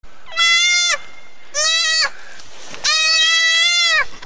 Звук испуганного крика детёныша кабарги (малыш кричит от страха)